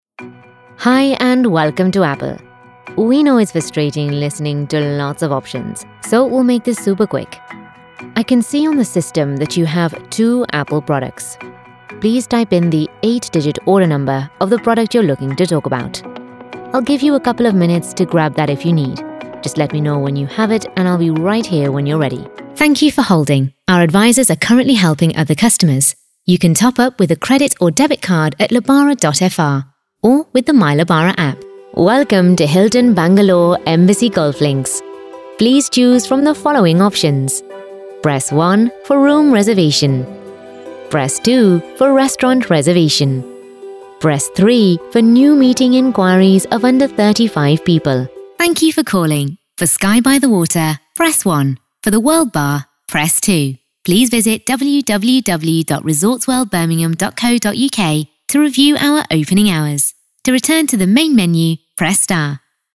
Anglais (Britannique)
Naturelle, Enjouée, Urbaine, Amicale, Chaude
Téléphonie